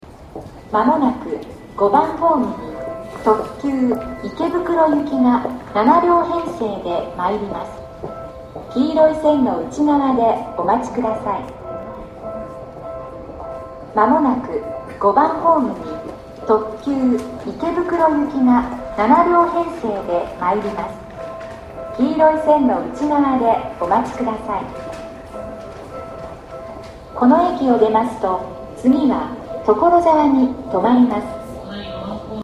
５番ホームSI：西武池袋線
接近放送特急　池袋行き接近放送です。